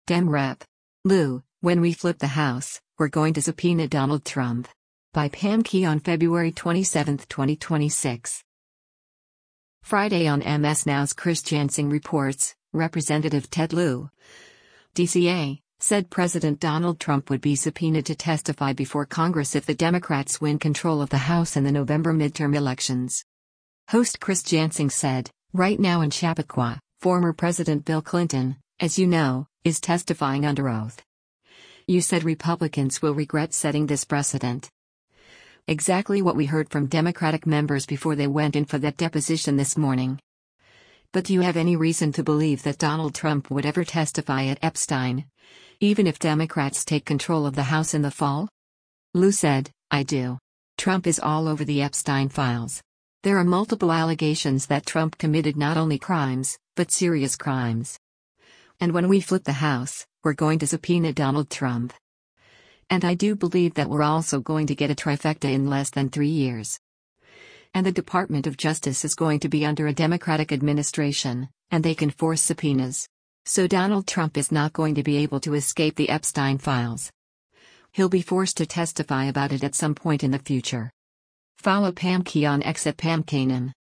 Friday on MS NOW’s “Chris Jansing Reports,” Rep. Ted Lieu (D-CA) said President Donald Trump would be subpoenaed to testify before Congress if the Democrats win control of the House in the November midterm elections.